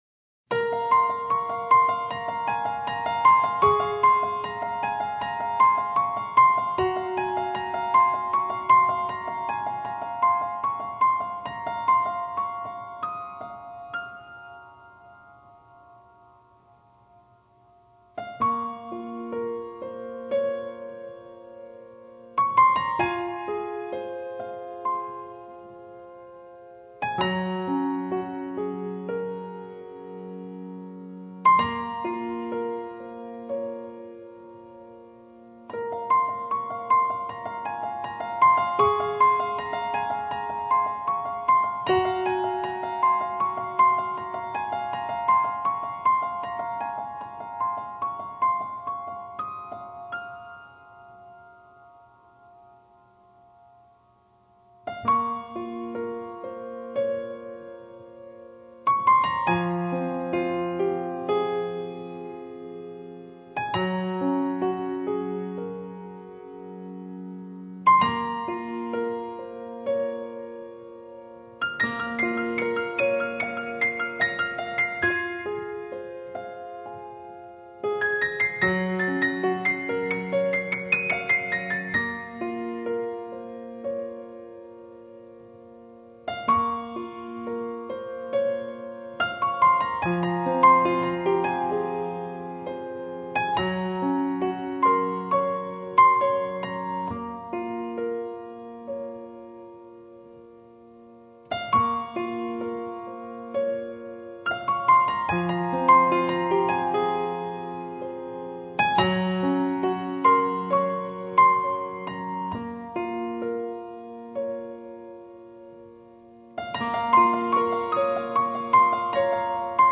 曲风缓慢恬静，带着丝丝淡淡的忧伤